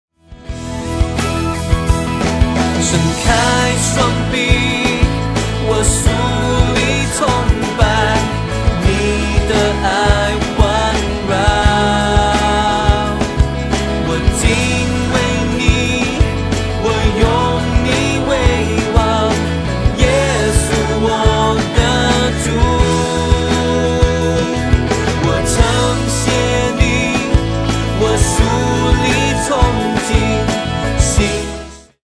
Mandarin Worship Album